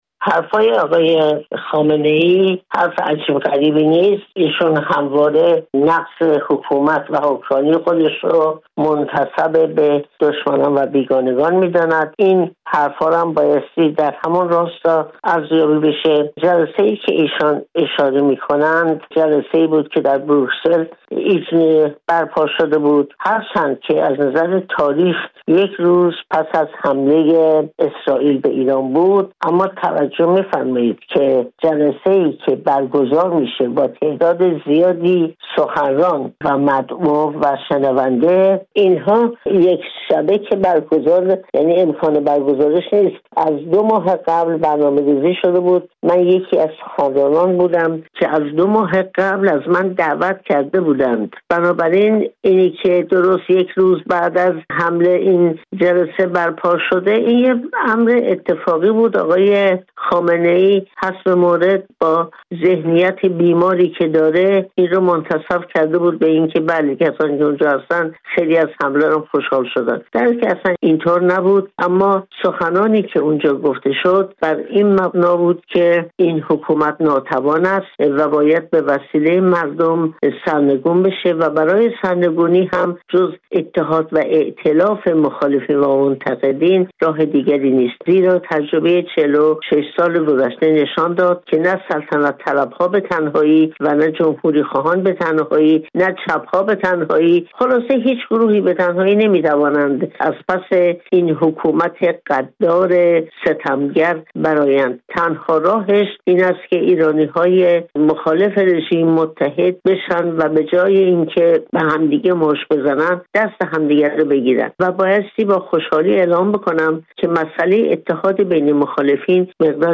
علی خامنه‌ای، رهبر جمهوری اسلامی، روز دوم شهریور در دیدار با هوادارانش ادعا کرد که تنها یک روز پس از حملهٔ ارتش اسرائیل به مقامات ارشد نظامی ایران، برخی قدرت‌های غربی همراه با «یک فرد ایرانی» در اروپا نشستی برگزار کردند تا دربارهٔ «جایگزین جمهوری اسلامی» تصمیم‌گیری کنند. در همین زمینه با شیرین عبادی برنده صلح نوبل گفت‌وگو کرده‌ایم.